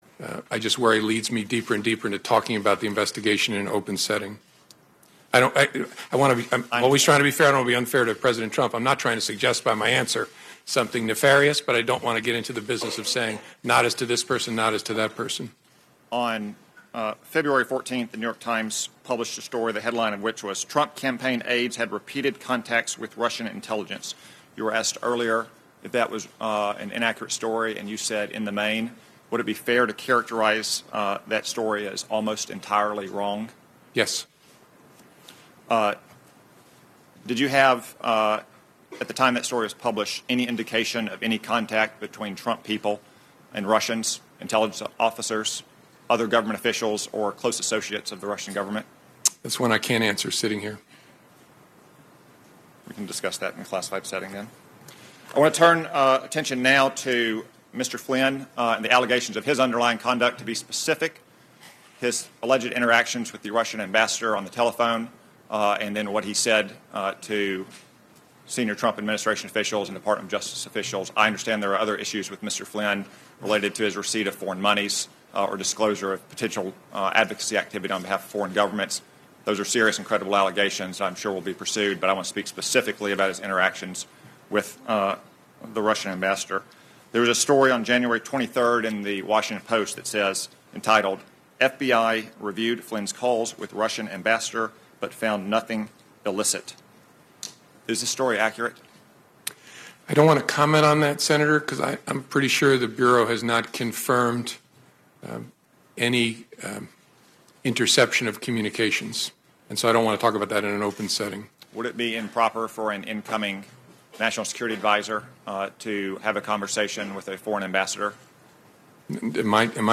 Former FBI Director James Comey testified in an open session of the Senate Intelligence Committee about his interactions with President Trump as well as the Bureau's investigation into Russian interference with the 2016 presidential election. This is the hearing, as it aired on KSL Newsradio.